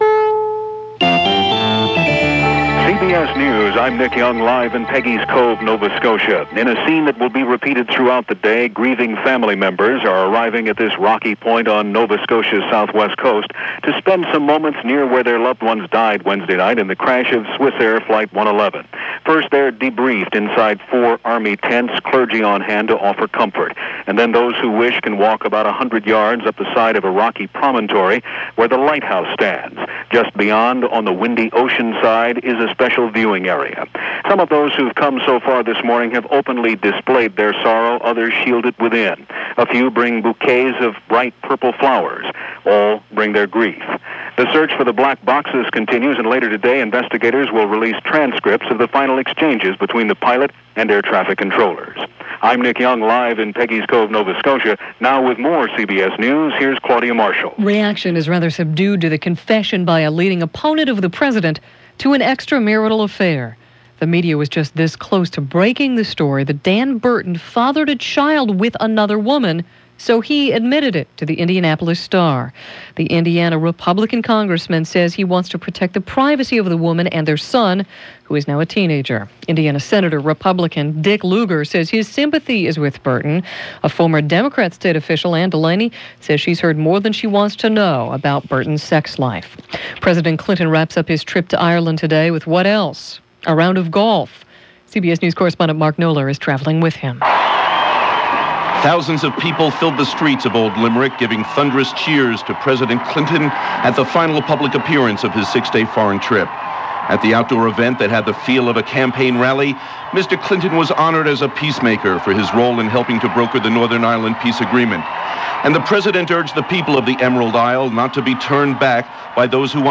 And aside from the ongoing story of Swissair Flight 111, that’s a small slice of what went on, this September 6, 1998 from CBS Radio, including President Clinton’s weekly Saturday Address.